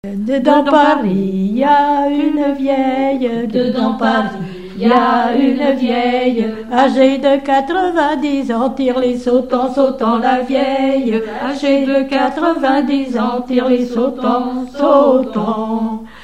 Usage d'après l'informateur gestuel : danse
Genre laisse
Pièce musicale inédite